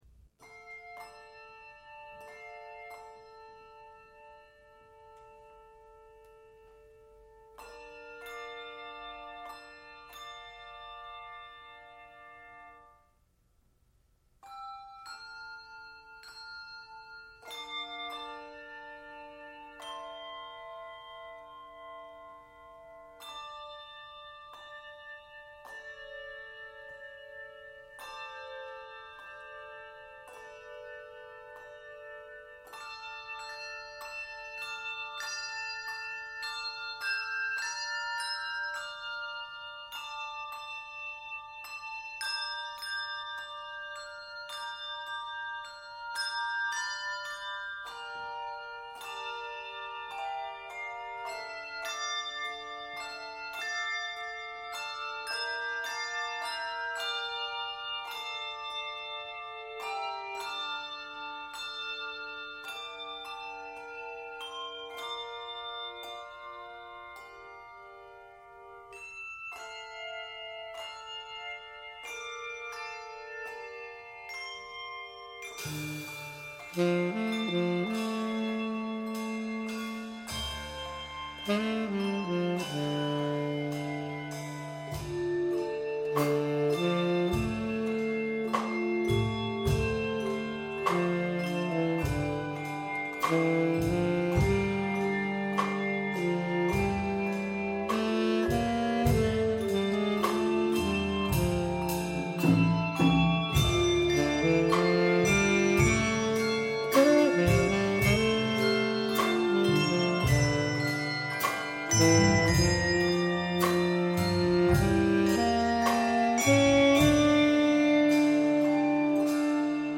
Soulful and stirring describe this arrangement
Keys of d minor, e minor, and c minor.